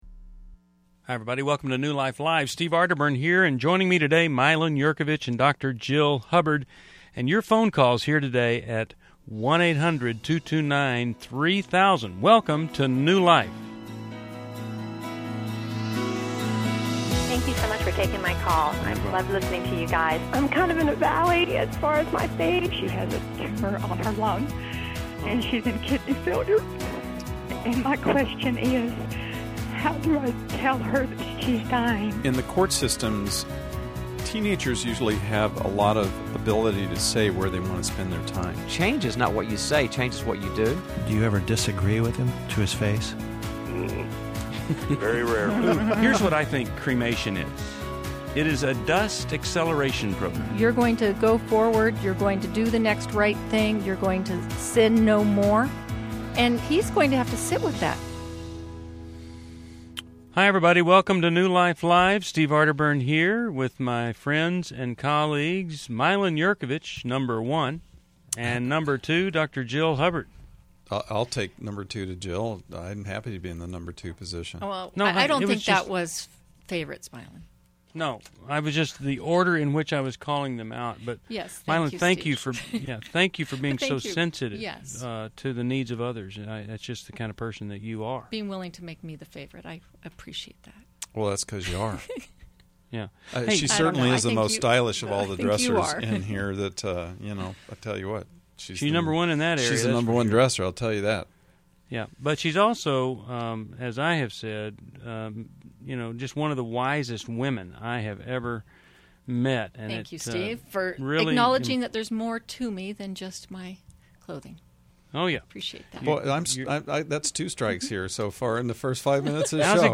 Explore sexual addiction, boundaries, and people-pleasing in relationships on New Life Live: July 1, 2011. Real callers share their struggles and insights.